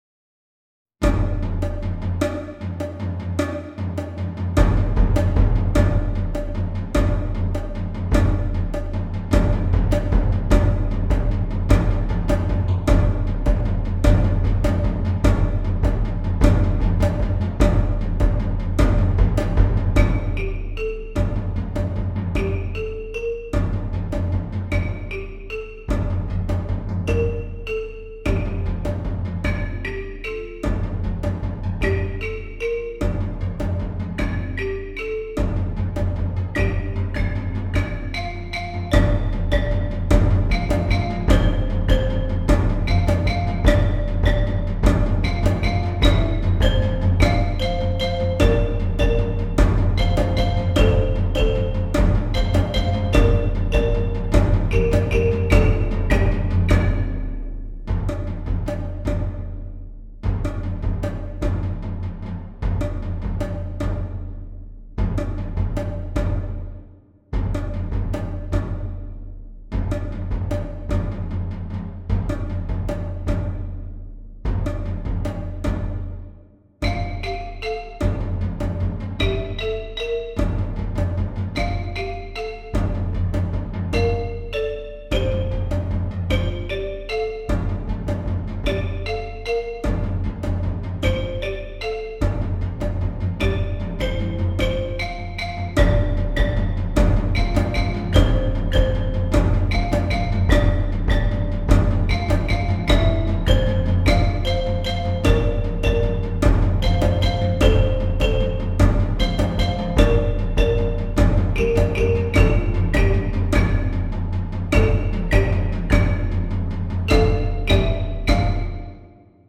Percussion Ensemble
Based on the elements of traditional African music